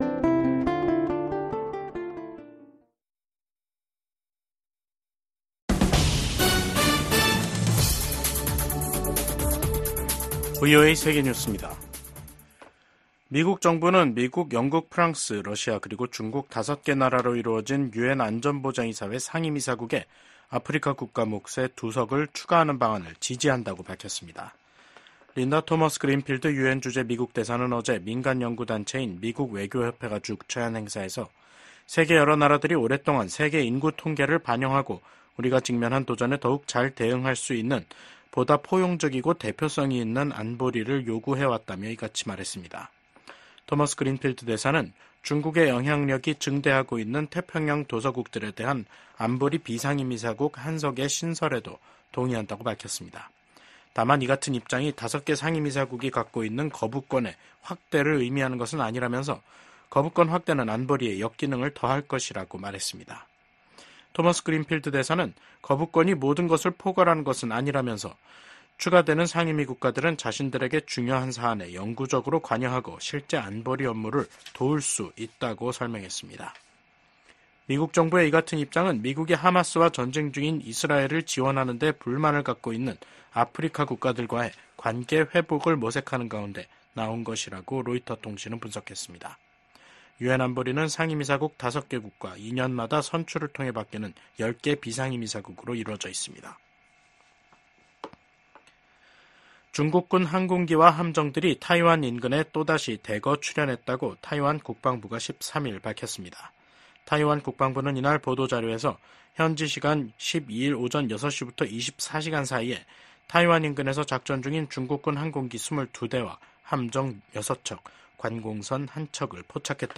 VOA 한국어 간판 뉴스 프로그램 '뉴스 투데이', 2024년 9월 13일 2부 방송입니다. 북한이 핵탄두를 만드는 데 쓰이는 고농축 우라늄(HEU) 제조시설을 처음 공개했습니다. 미국과 리투아니아가 인도태평양 지역에 대한 고위급 대화를 개최하고 러시아의 북한제 탄도미사일 사용을 비판했습니다.